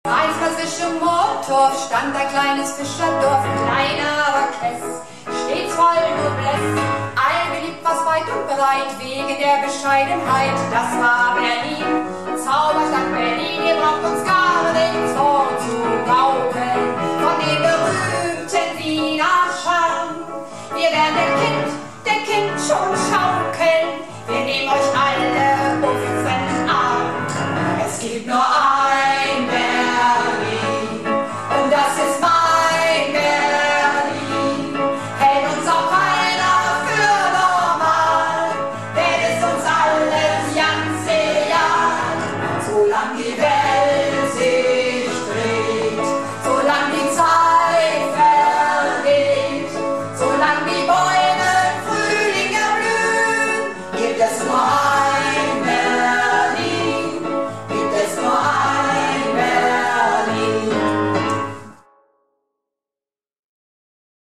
Theater - "Du musst es wagen - Sünner Tegenstöten word nümms deftig" am 18.03.2012 in Emden